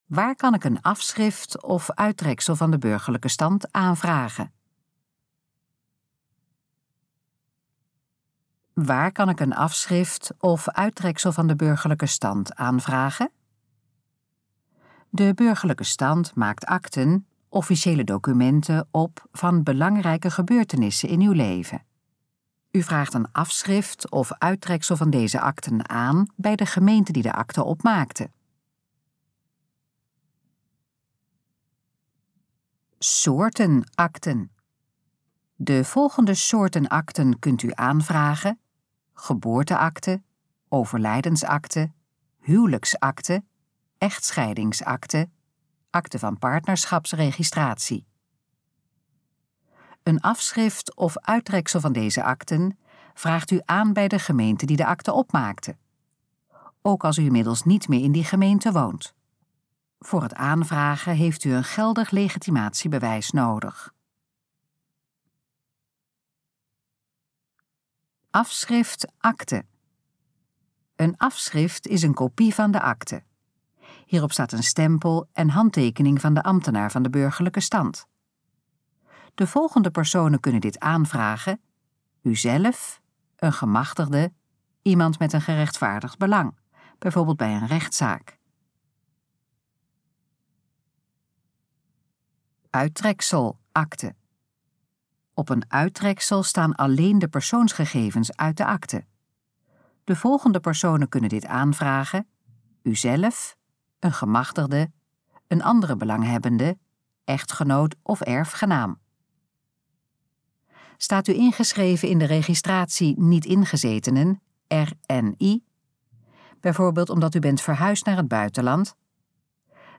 Gesproken versie van: Waar kan ik een afschrift of uittreksel van de burgerlijke stand aanvragen?
Dit geluidsfragment is de gesproken versie van de pagina: Waar kan ik een afschrift of uittreksel van de burgerlijke stand aanvragen?